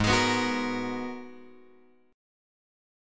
G#m9 chord {4 x 6 4 7 6} chord